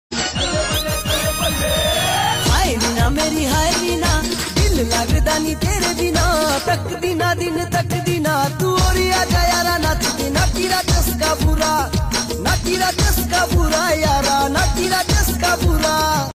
soulful Himachali beats
Pahadi music